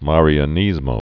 (mârē-ə-nĭzmō, măr-)